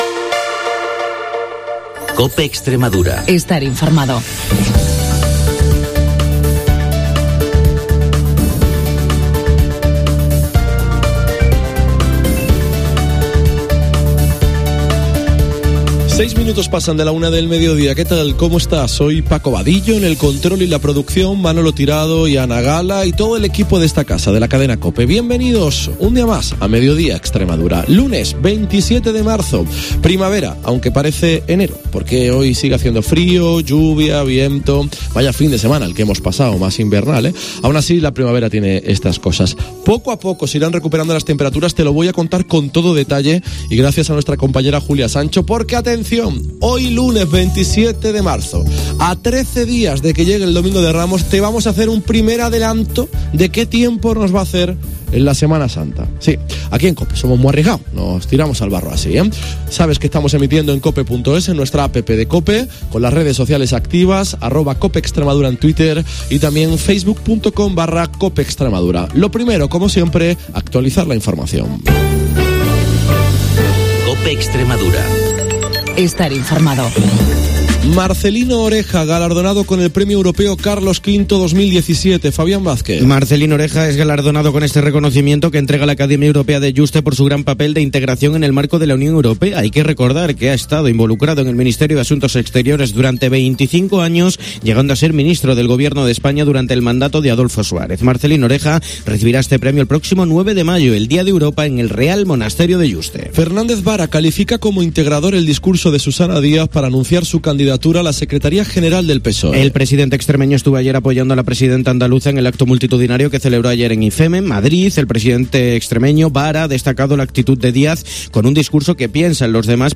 Hoy hemos avanzado el tiempo previsto para la próxima SEMANA SANTA y también hemos entrevistado a José Antonio Monago, reelegido presidente del PP de Extremadura.